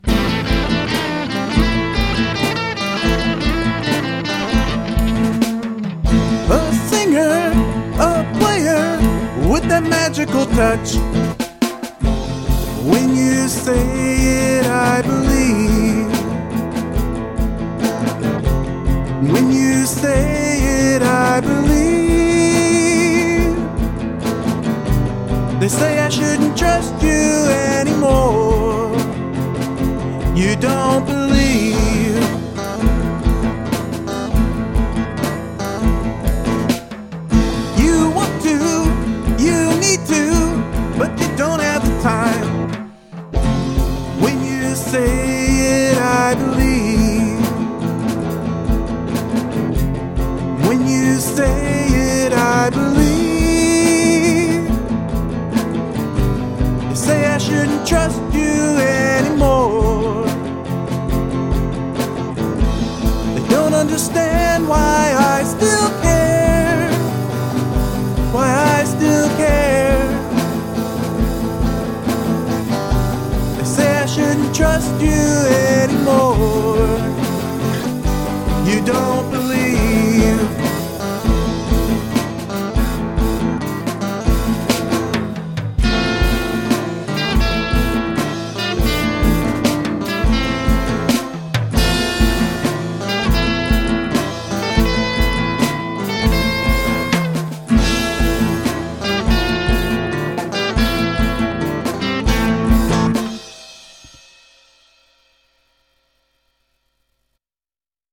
Apparently it's in an odd time signature.